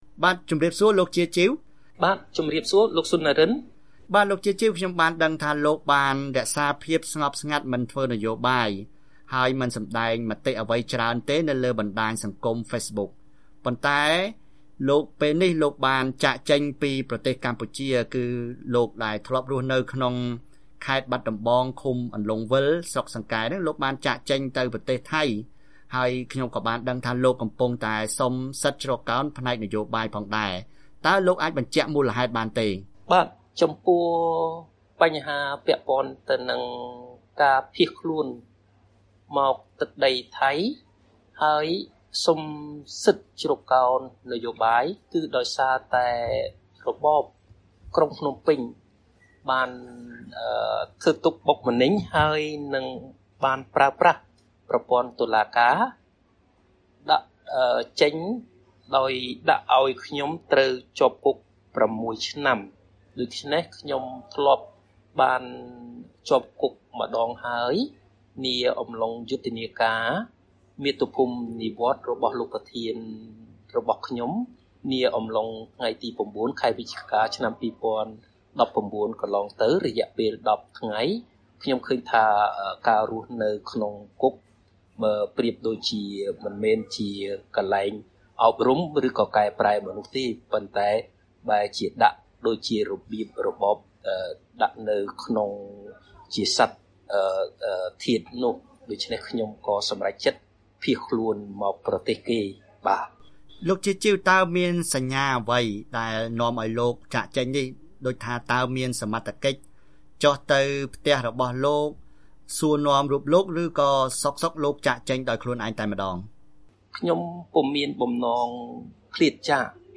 បទសម្ភាសន៍ VOA៖ អតីតមន្រ្តីគណបក្សសង្រ្គោះជាតិនៅខេត្តបាត់ដំបងភៀសខ្លួនសុំសិទ្ធិជ្រកកោននយោបាយ